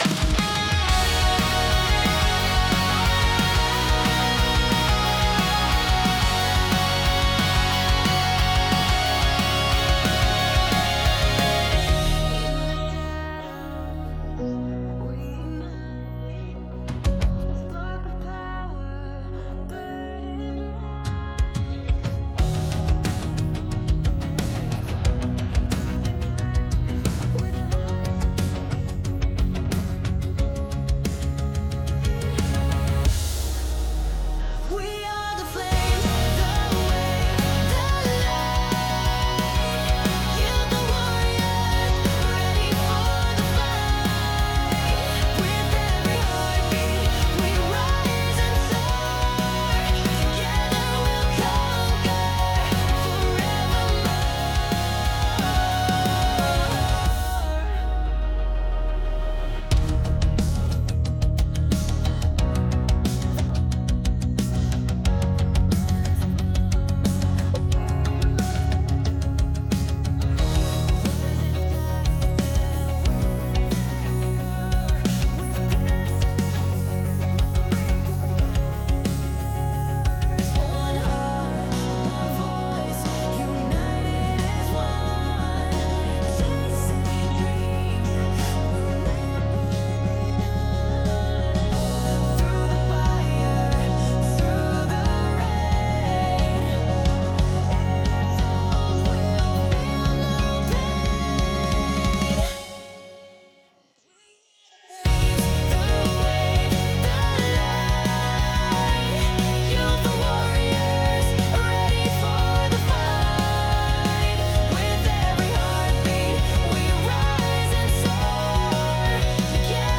格闘系アニメ風の画像を使用した生成例では、画像の躍動感を的確に捉えたアップテンポで力強い楽曲が生成されました。